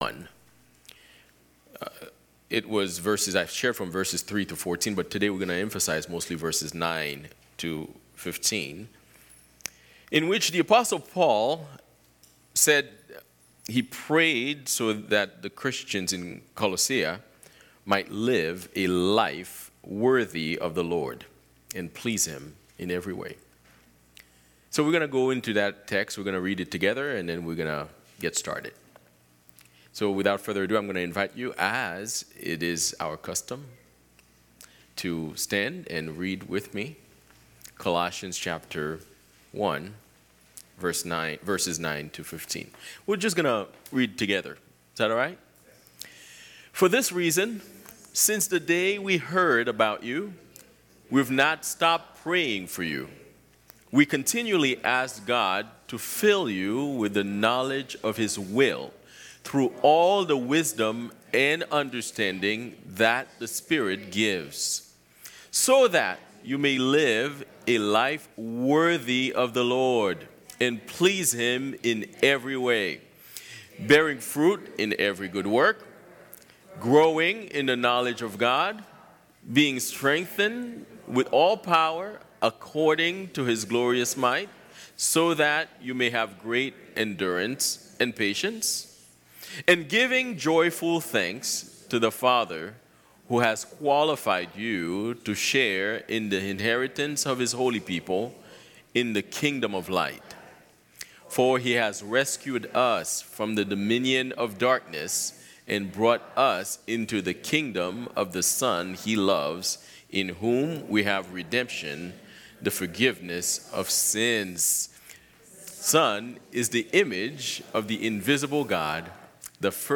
Sermons by Fellowship Church Dedham